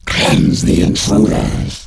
alien_gamestart2.wav